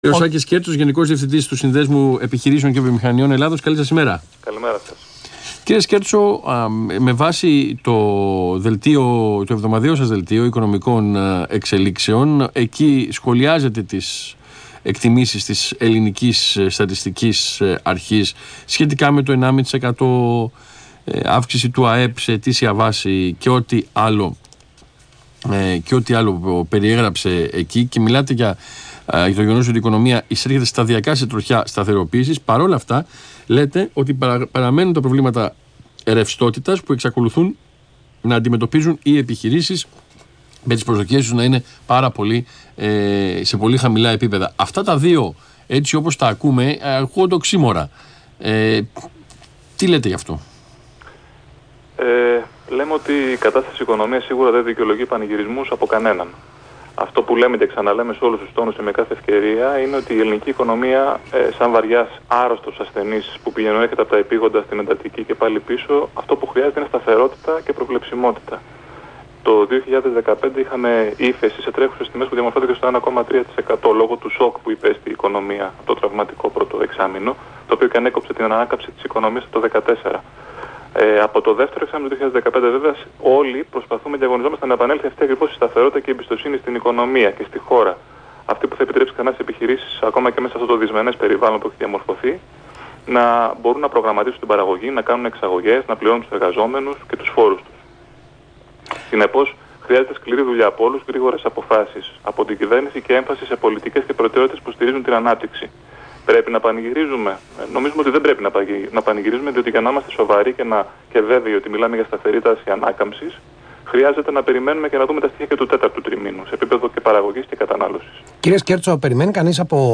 Συνέντευξη του Γενικού Διευθυντή του ΣΕΒ κ. Άκη Σκέρτσου στον Ρ/Σ Αθήνα 9.84, 15/11/2016